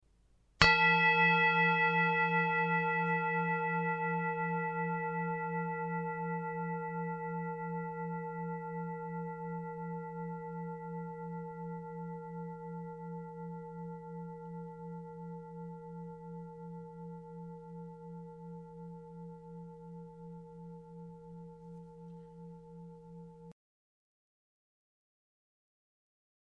Tibetische Klangschale - UNIVERSALSCHALE
Durchmesser: 19,0 cm
Grundton: 166,91 Hz
1. Oberton: 468,34 Hz